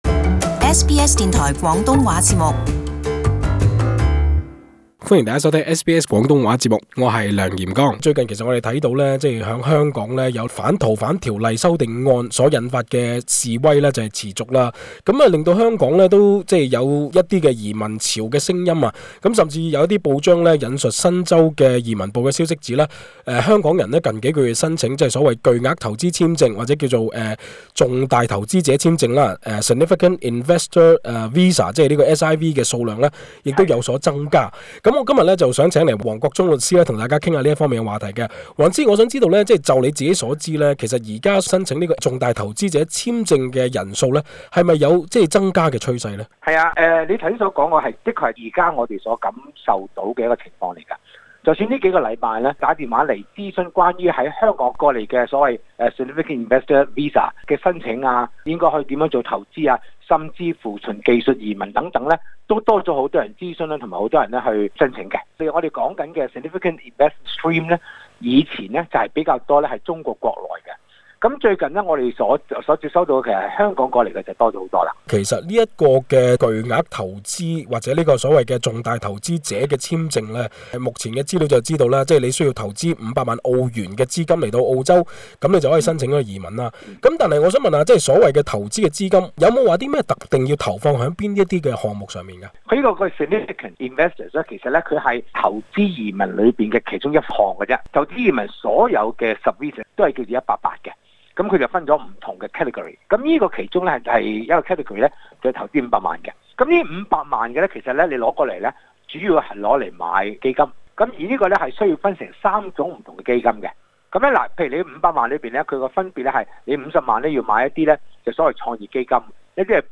【社區專訪】懶人包：澳洲移民辦法逐個數